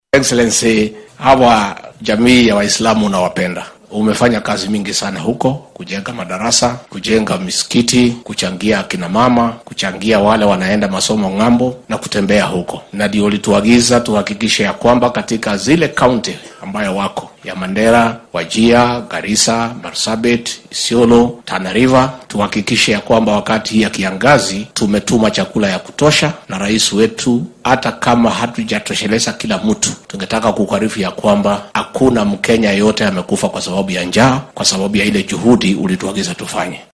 Madaxweyne ku xigeenka dalka Rigathi Gachagua ayaa xilli uu xalay ka qayb galay munaasabad afur ah oo lagu qabtay xarunta shirarka caalamiga ee KICC ee magaalada Nairobi waxaa uu sheegay in ilaa iyo hadda uunan jirin qof kenyaan ah oo u geeriyooday saameynta abaarta. Waxaa uu carrabka ku adkeeyay in gargaar cunno la gaarsiiyay ismaamullada ay abaarta sida gaarka ah u saameysay oo ay ka mid yihiin kuwo waqooyi bari.